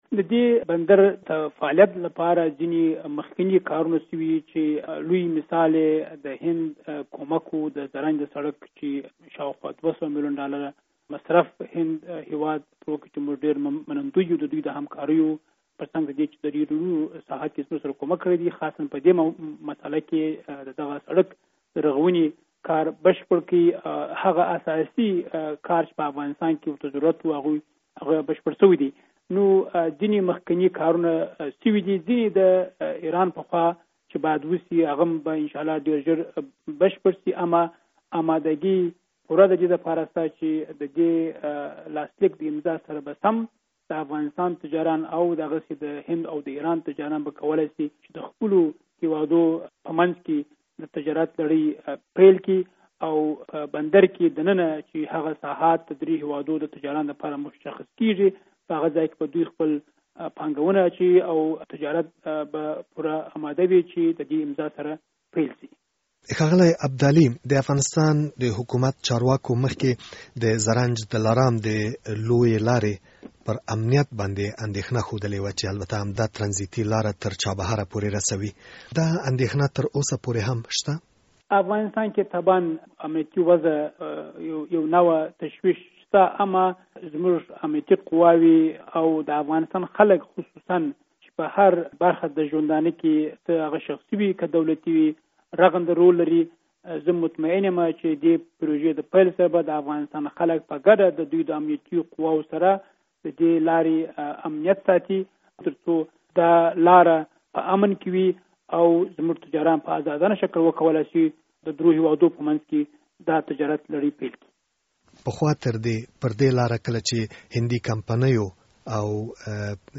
په نوې ډیلي کې د افغانستان له سفیر شیدا محمد ابدالي سره مرکه